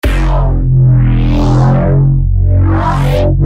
After resampling
Synth bass sound
Good ol' LP filtering with post-filter distortion.
bass_resampled.mp3